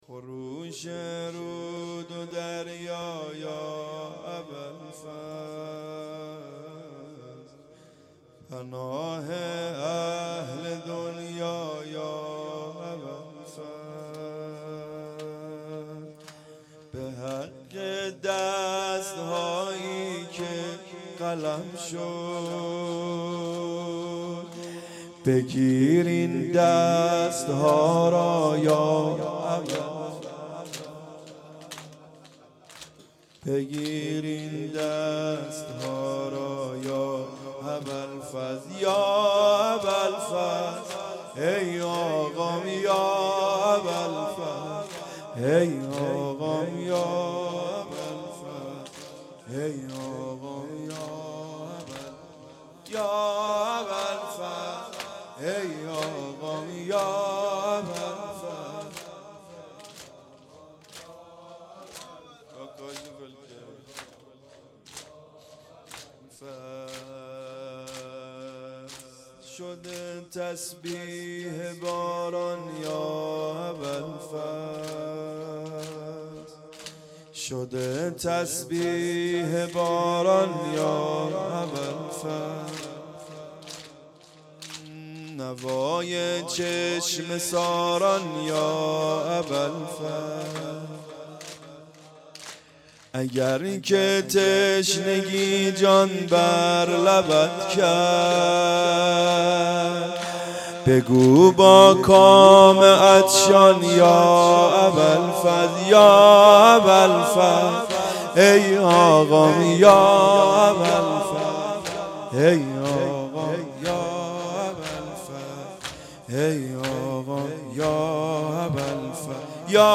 خروش رو دو دریا یا اباافضل _ زمینه
محرم 1440 _ شب نهم